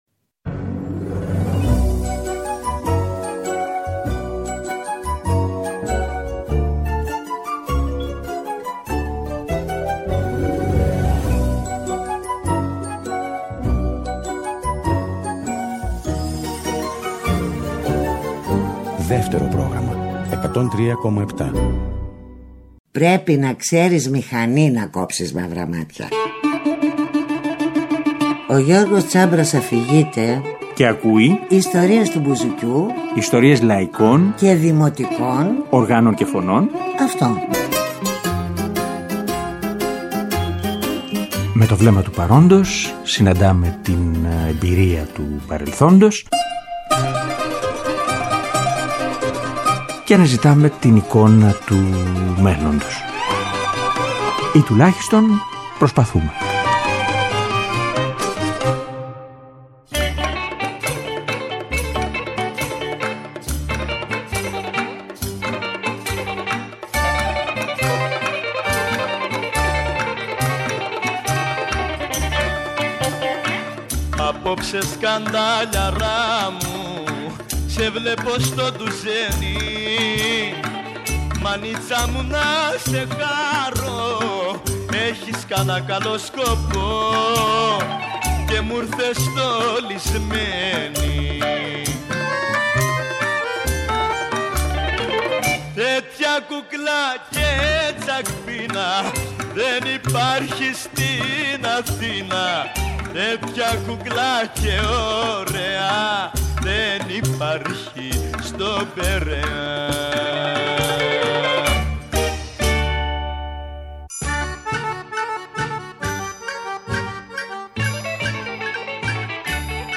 Τραγούδια με στίχους του Χρήστου Κολοκοτρώνη (β’ μέρος).
Τρίτη 22 και Τετάρτη 23/11/22, 9 με 10 το βράδυ, στο Δεύτερο Πρόγραμμα.